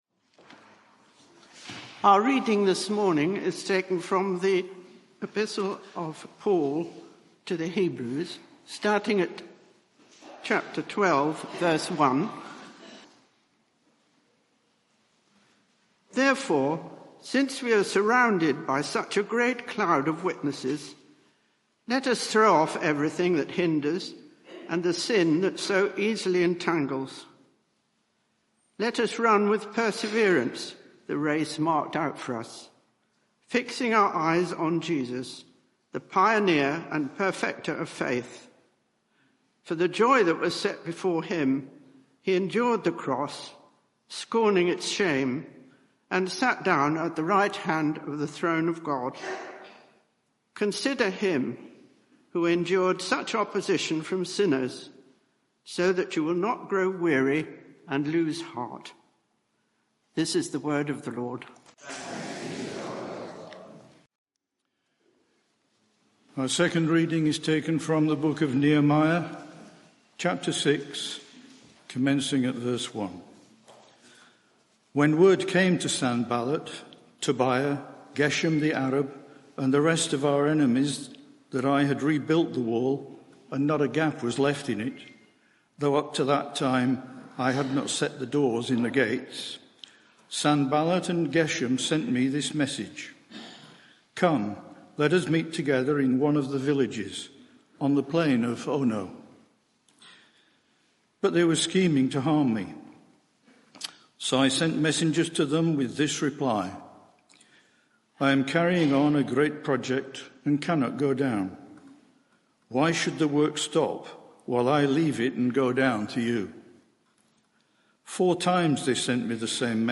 Media for 11am Service on Sun 12th May 2024 11:00 Speaker
Series: Building God's City Theme: Nehemiah 6:1-7:73 Sermon (audio)